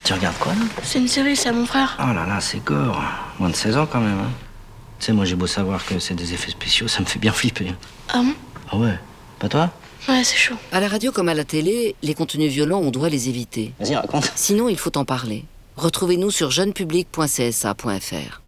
Les radios se mobilisent également en diffusant un message audio, écho des campagnes télévisuelles, rappelant aux parents et aux adultes responsables d’enfants et d’adolescents, la nécessité de superviser les programmes regardés par les mineurs et de provoquer un échange sur les images visualisées.